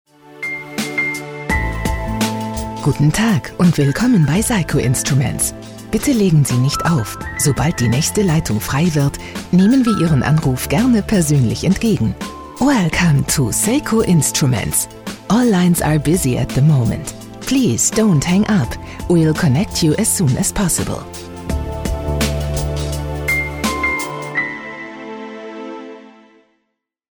deutsche Profi-Sprecherin, einfühlsame, warme, sanfte, meditative, erotische oder auch zickige oder laute Stimme
Sprechprobe: Industrie (Muttersprache):
german female voice over artist